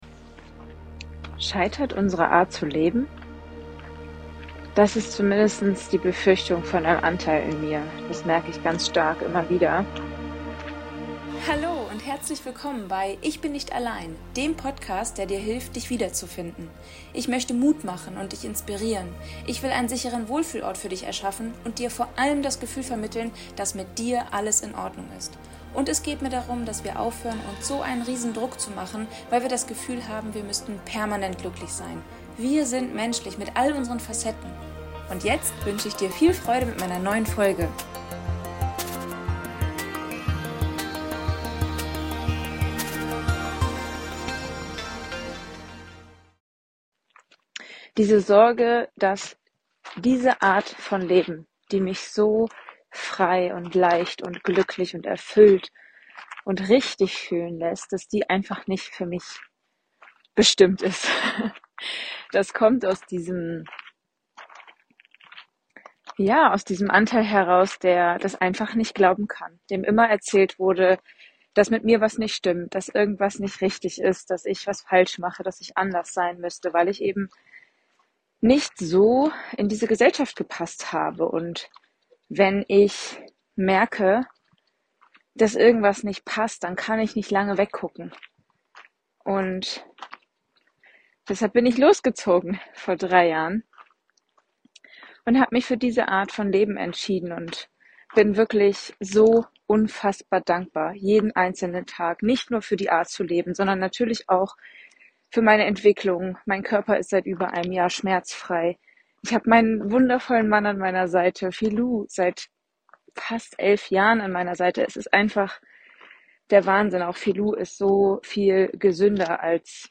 In dieser Folge nehme ich dich mit auf meinen Morgenspaziergang durch Prilep (Nordmazedonien) und in meine inneren Welten: zwischen Dankbarkeit für ein Leben voller Freiheit, Liebe und Kreativität – und der Frage, ob genau dieses Leben überhaupt „funktionieren“ kann.